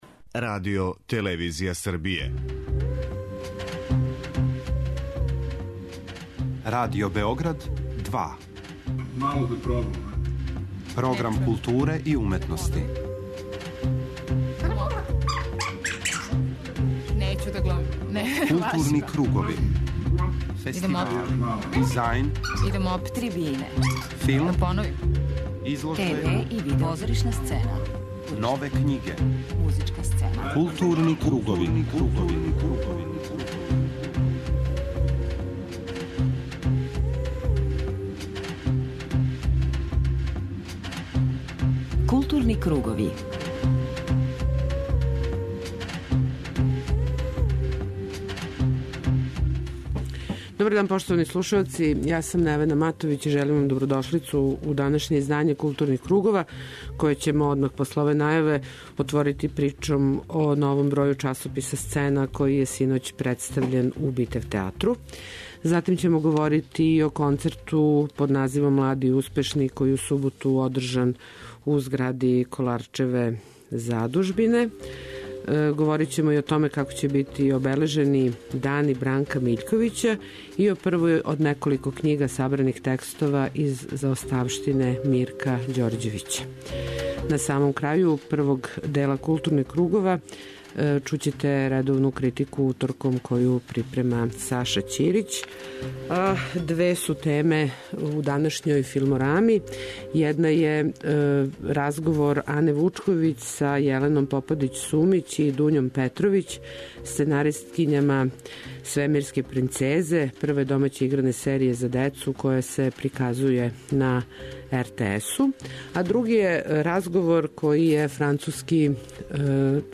У сусрет Филмском фестивалу у Кану, а поводом вести да ће два филма подржана од стране Филмског центра Србије бити приказана у званичној селекцији, чућете разговор са директором и селектором фестивала Тијеријем Фремоом, који је са новинарима водио после радионице одржане на филмском и музичком фестивалу Кустендорф.